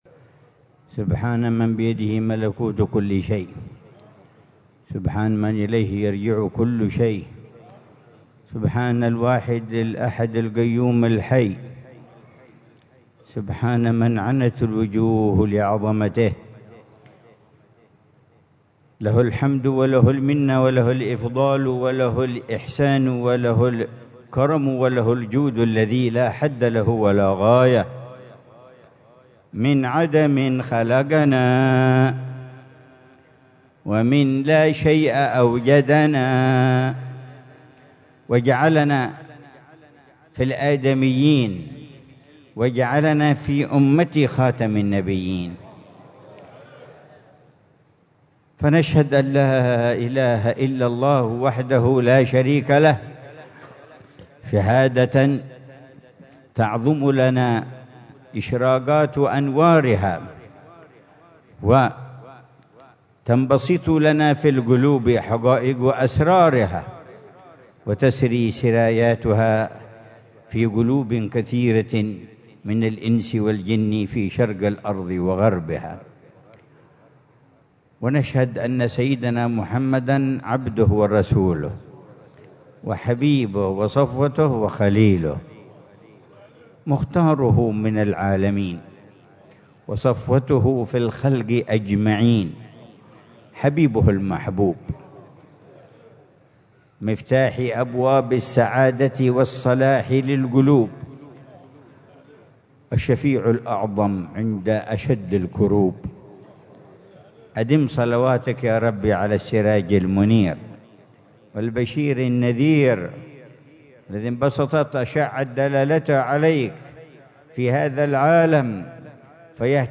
محاضرة العلامة الحبيب عمر بن محمد بن حفيظ ضمن سلسلة إرشادات السلوك في دار المصطفى، ليلة الجمعة 14 صفر الخير 1447هـ، بعنوان: